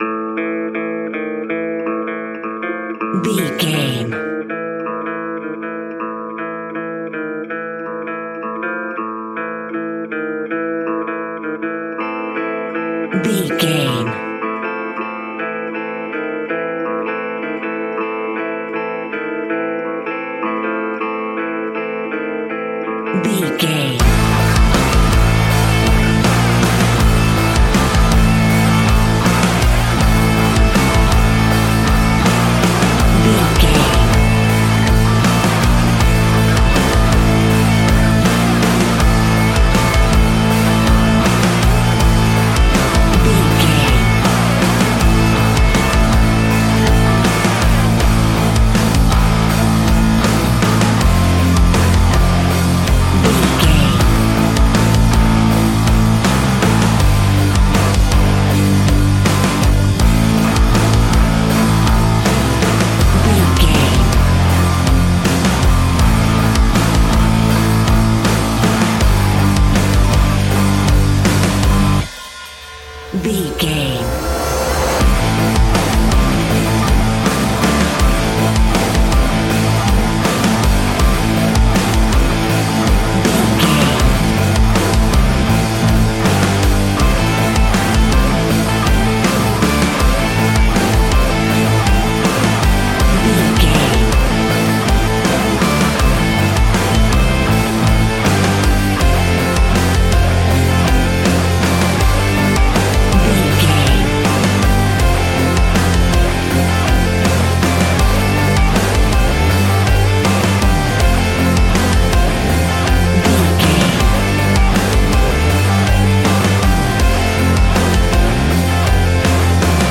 Ionian/Major
A♭
hard rock
guitars
heavy metal
instrumentals